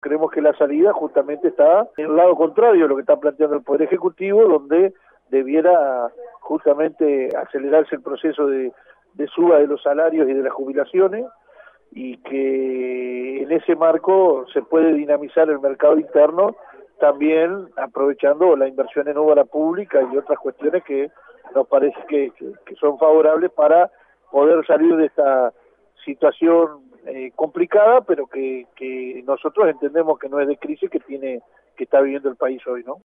810 VIVO